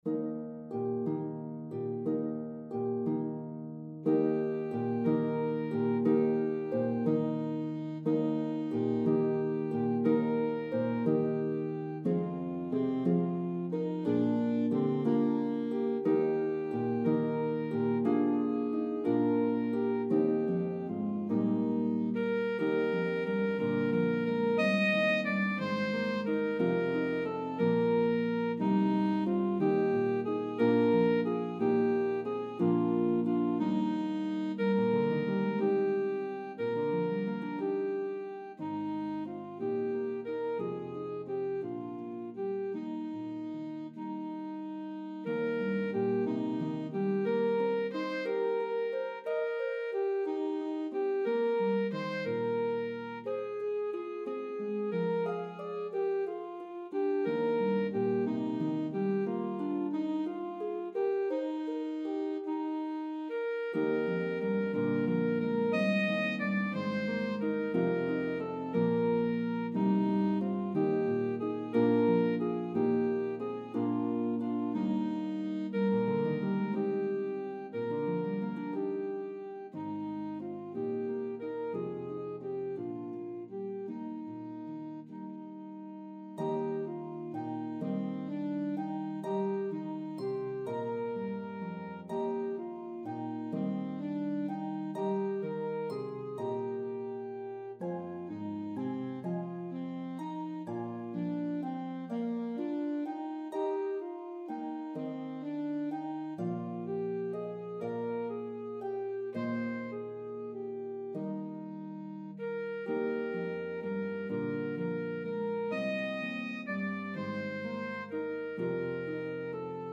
traditional Christmas hymn
Harp and Alto Saxophone version